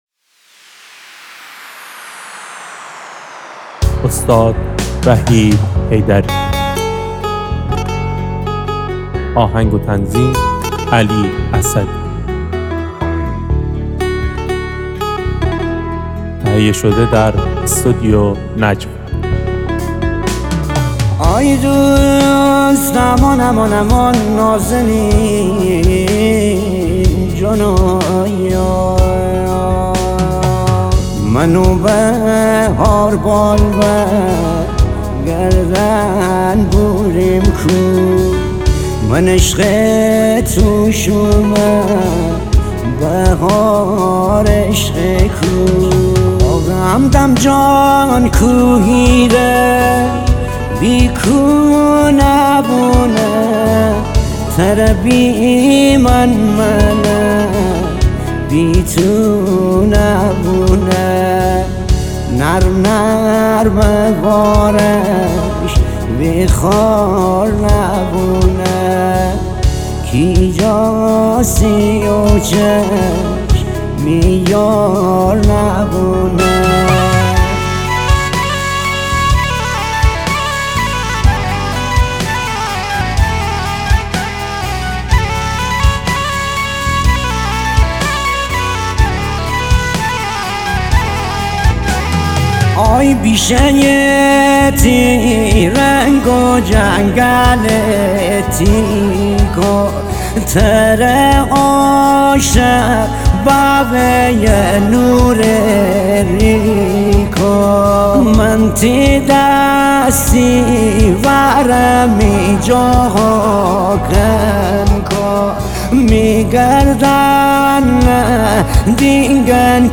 آهنگی در سبک آهنگ های ریمیکس مازندرانی